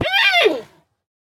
sounds / mob / panda / death3.ogg